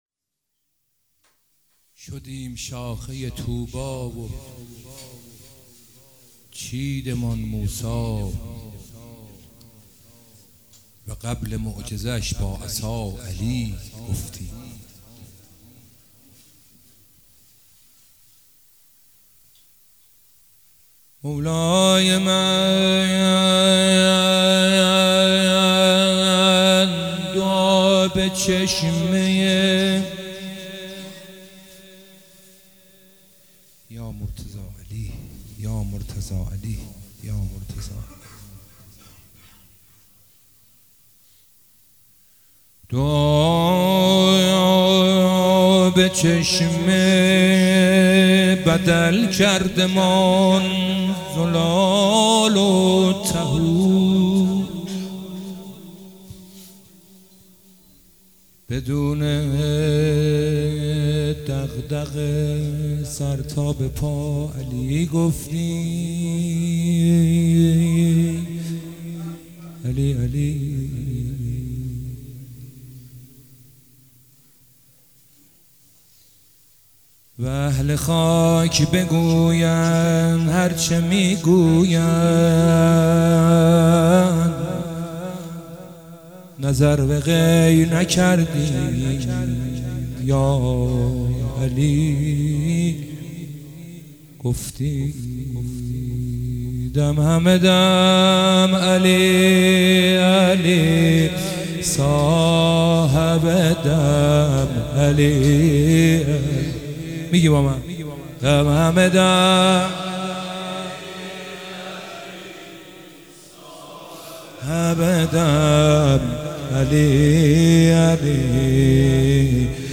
مدح مولا امیرالمومنین (ع) - مدح خوانی شب بیست و یکم ماه رمضان شب قدر 1446
شب 21 ماه رمضان 1446